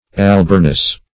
Search Result for " alburnous" : The Collaborative International Dictionary of English v.0.48: Alburnous \Al*bur"nous\, a. Of or pertaining to alburnum; of the alburnum; as, alburnous substances.